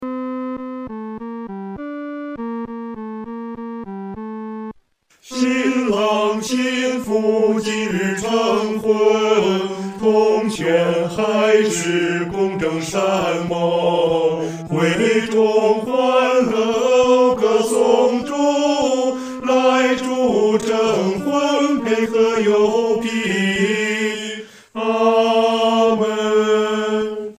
女低
本首圣诗由网上圣诗班录制